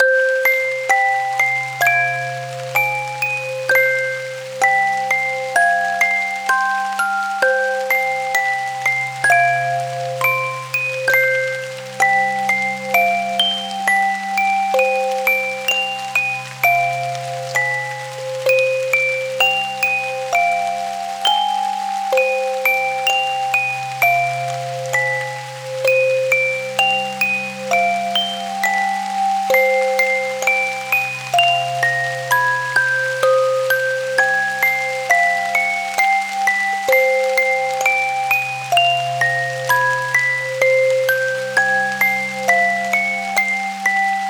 Loop